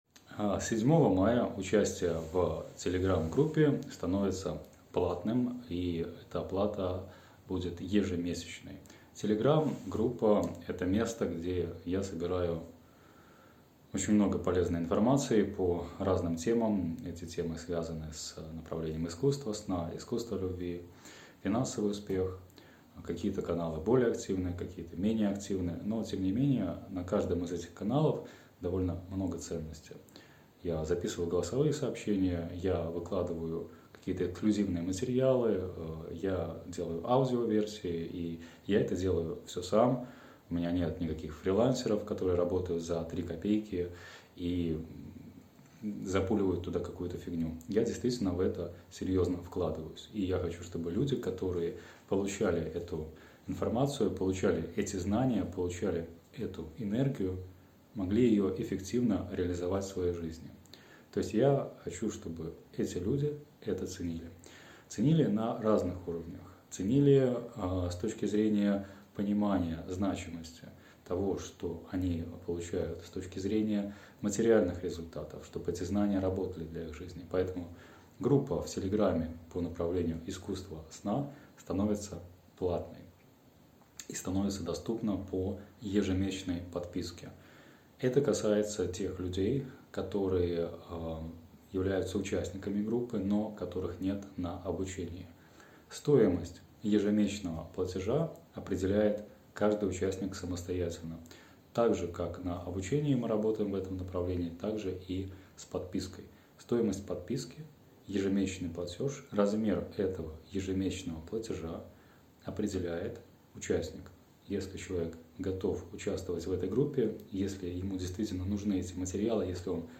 Голосовая заметка